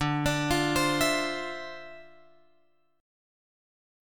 D Minor 9th